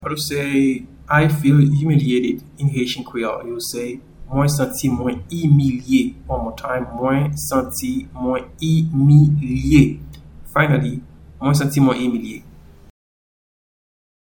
Pronunciation and Transcript:
I-feel-humiliated-in-Haitian-Creole-Mwen-santi-mwen-imilye.mp3